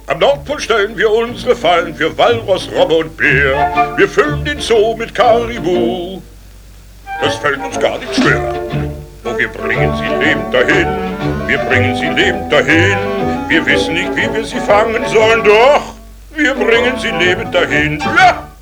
VHS-Fassung